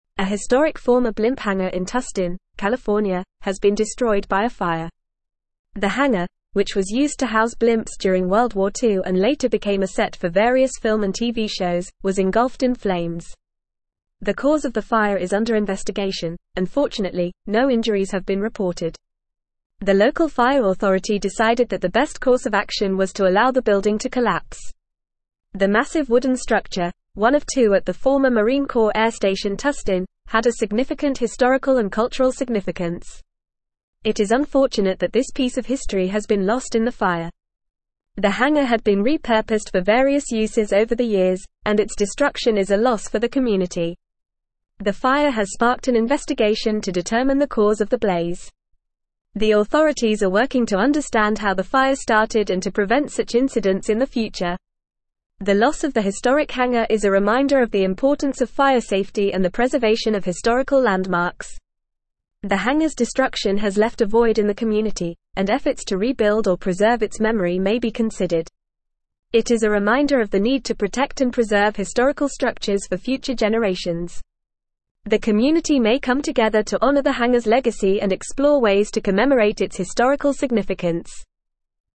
Fast
English-Newsroom-Advanced-FAST-Reading-Historic-World-War-Two-Era-Blimp-Hangar-Engulfed-in-Flames.mp3